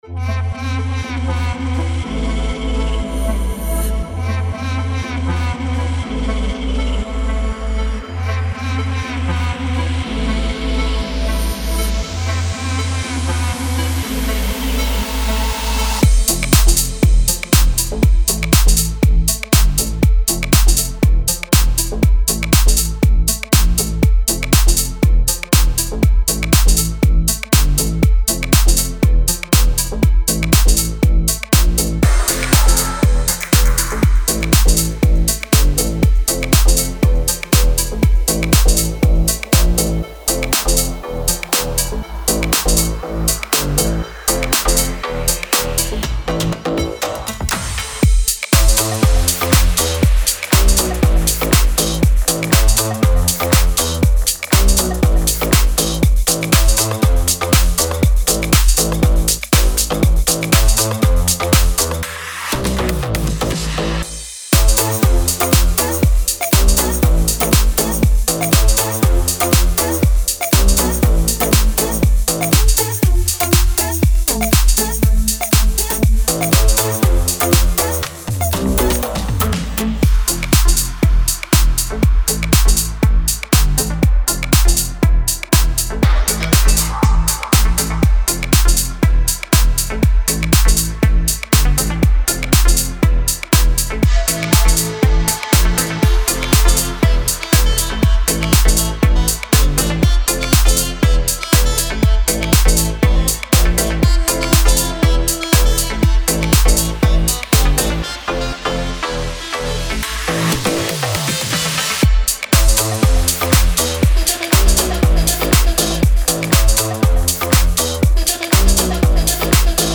Структура House трека.